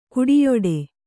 ♪ kuḍiyoḍe